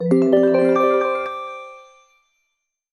En Güzel 2026 Bildirim Sesleri İndir - Dijital Eşik